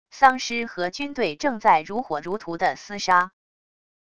丧尸和军队正在如火如荼地厮杀wav音频